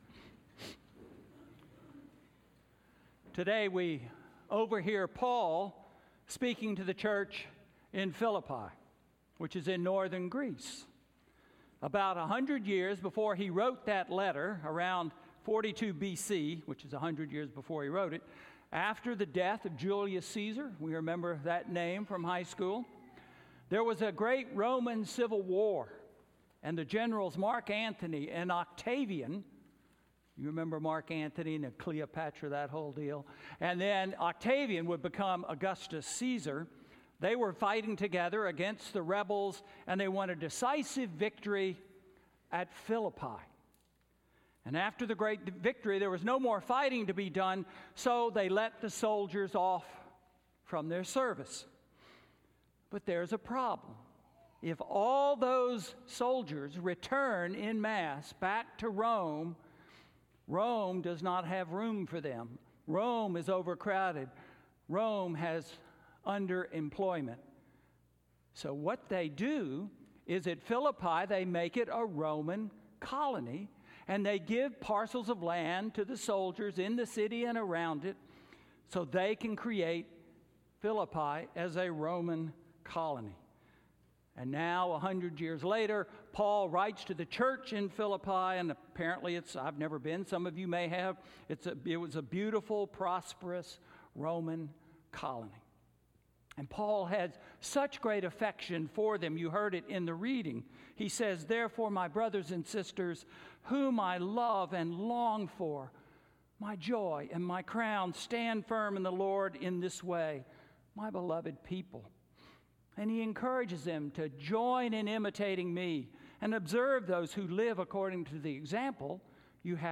Sermon–Where is my Citizenship? March 17, 2019